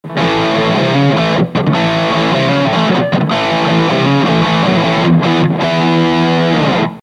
Guitar Fender STRTOCASTER
Amplifier VOX AD30VT AC15
GAIN全開VOLUME全開